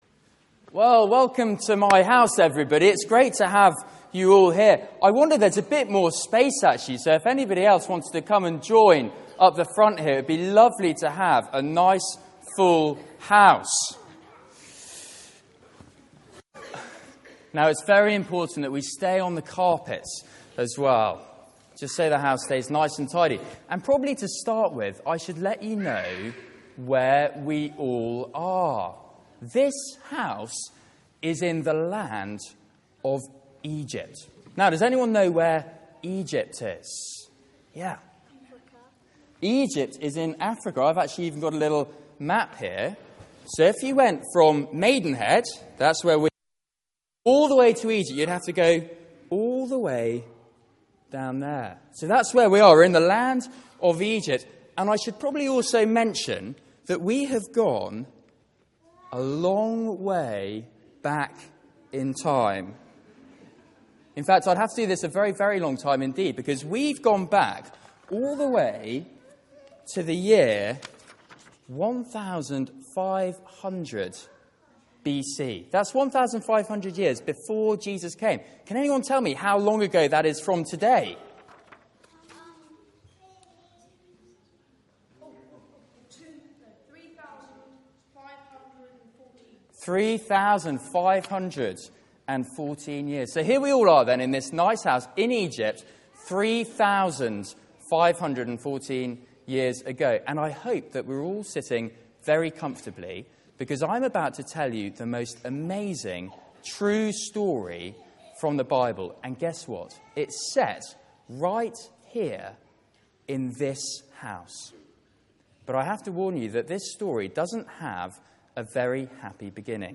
Media for 9:15am Service on Sun 09th Nov 2014 09:15 Speaker
Passage: Exodus 12:21-23, Luke 22:14-20 Series: Remembrance Sunday Theme: Sermon